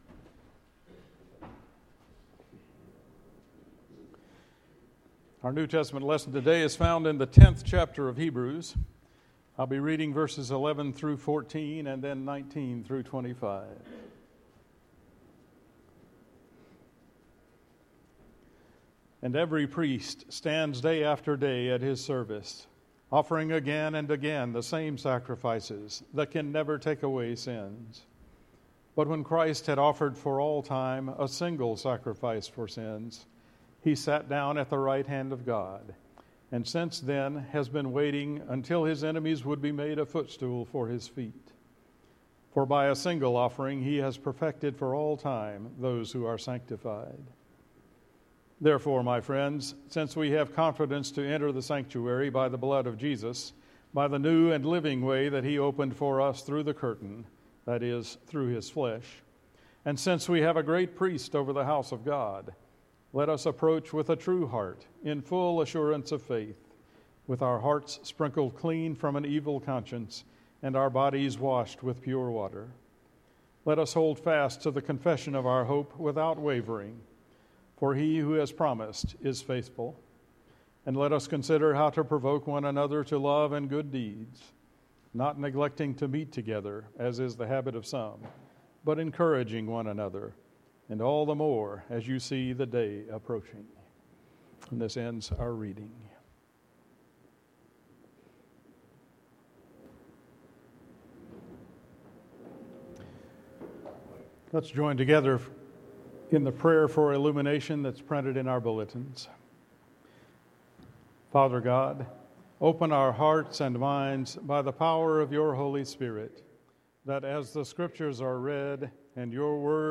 November 18 Worship Service